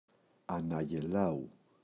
αναγελάου [anaγe’lau] – ΔΠΗ